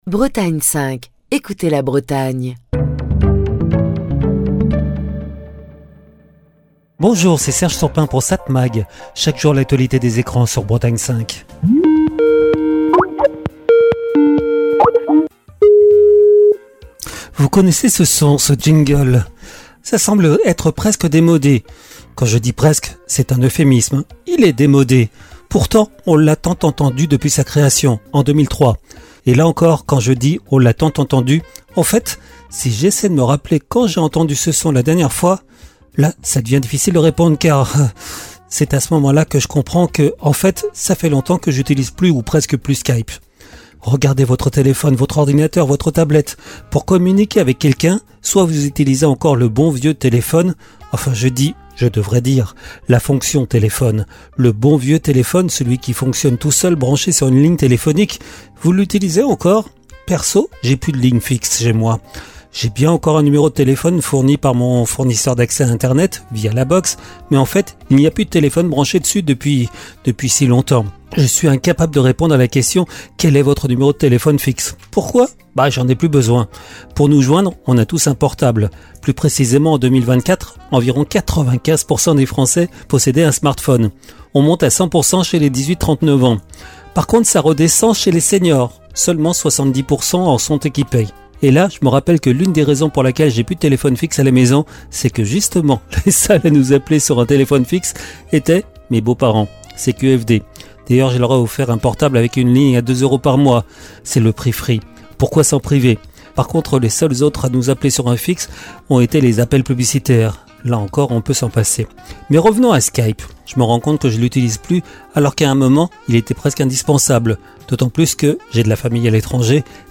Chronique du 6 mai 2025.